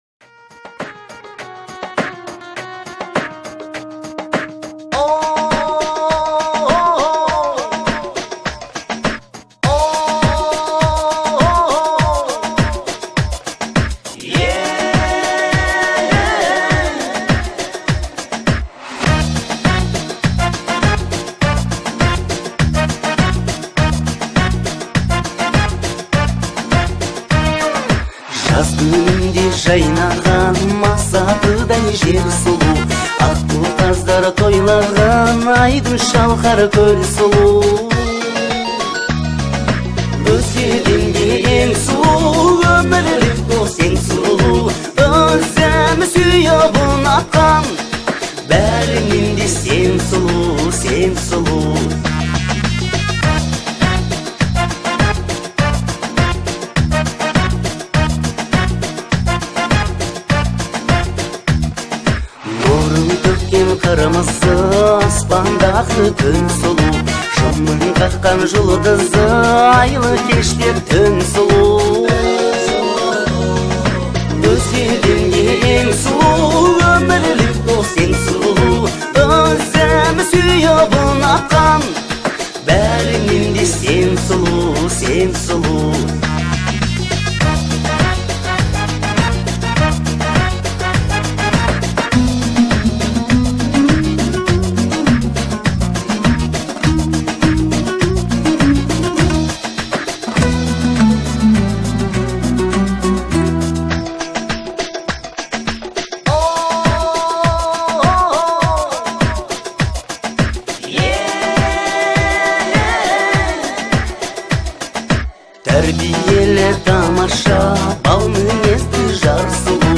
это романтическая песня в жанре казахской поп-музыки